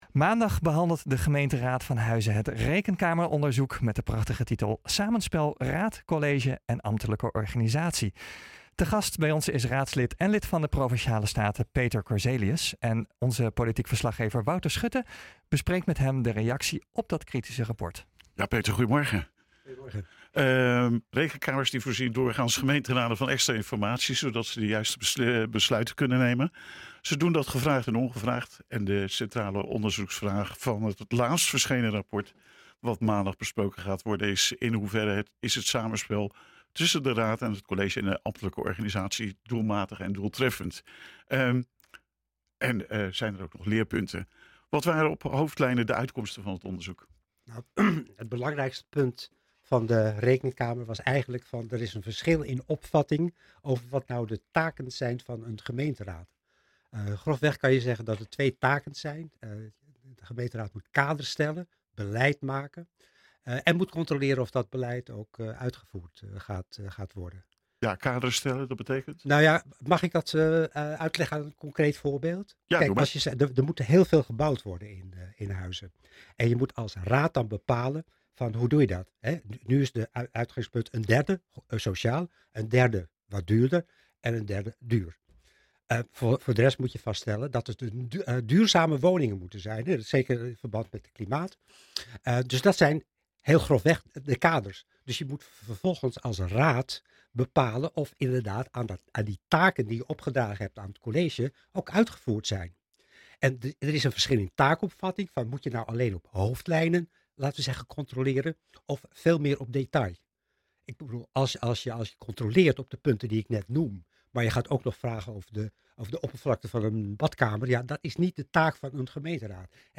Maandag behandelt de gemeenteraad van Huizen het Rekenkameronderzoek Samenspel raad, college en ambtelijke organisatie. Te gast: raadslid en lid van de Provinciale Staten Peter Korzelius.